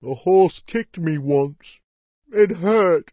PeasantPissed4.mp3